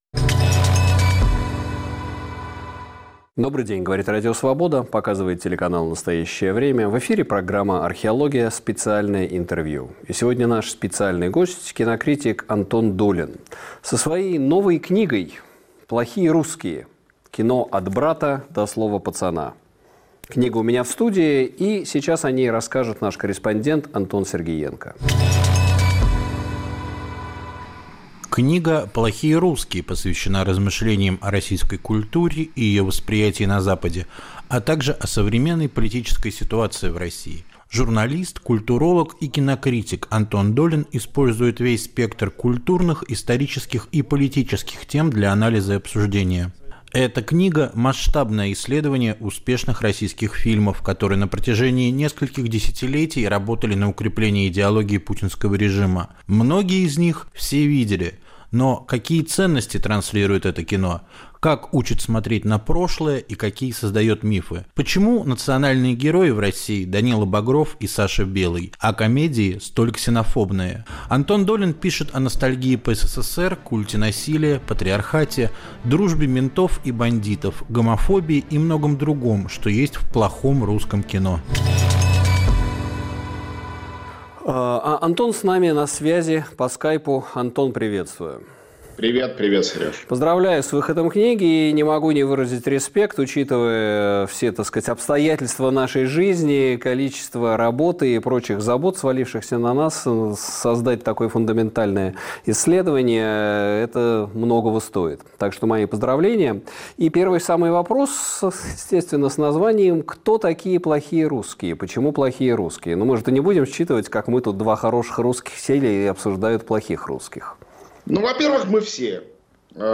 На вопросы Сергея Медведева отвечает кинокритик Антон Долин